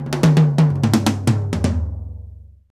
Index of /90_sSampleCDs/Roland L-CDX-01/TOM_Rolls & FX/TOM_Tom Rolls
TOM TOM R04L.wav